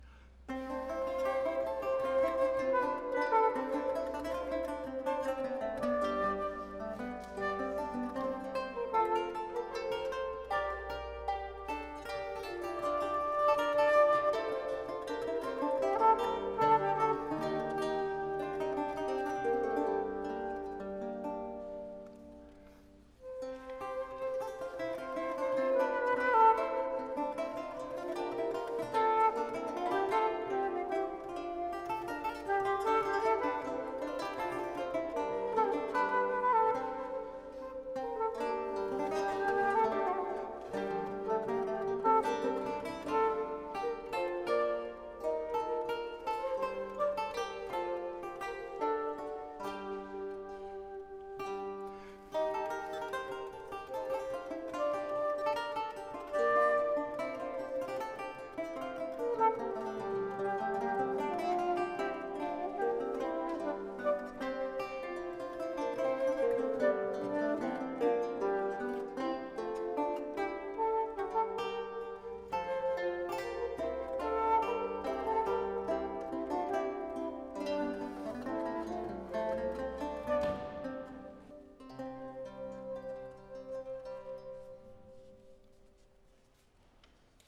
Un quartetto di musicisti cantanti, radunati insieme per l’occasione del Festival Cantar di Pietre dedicato a Venezia, ha dato vita a un progetto che si muove sulla messa in musica della storia di Orlando attingendo a fonti venete e a una traduzione in veneziano del poema dell’Ariosto.
voce, traversa
voce, claviciterio, organetto
voce, liuto
voce, arpa
Alcuni ascolti dal concerto di Biasca: